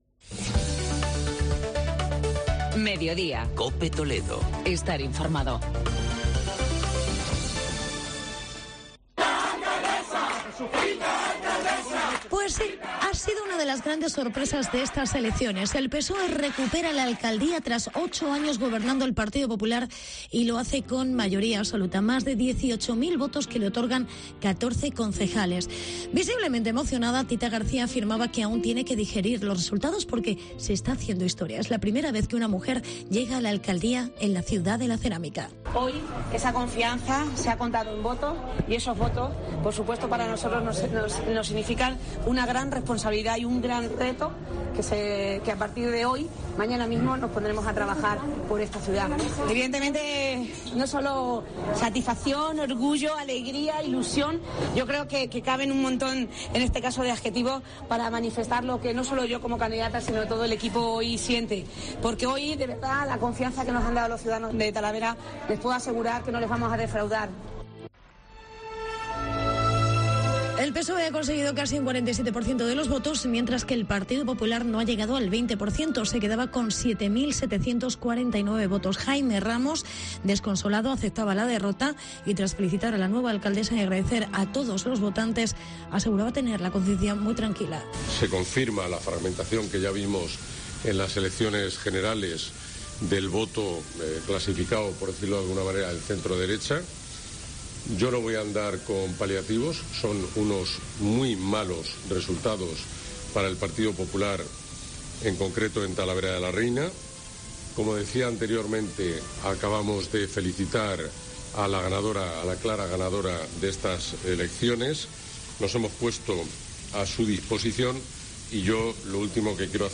Tita sorprende con mayoría absoluta en Talavera y Ramos se queda con 5 concejales. Reportaje